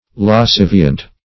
Search Result for " lascivient" : The Collaborative International Dictionary of English v.0.48: Lascivient \Las*civ"i*ent\ (l[a^]s*s[i^]v"[i^]*ent), a. [L. lasciviens, pr. of lascivire to be wanton, fr. lascivus.
lascivient.mp3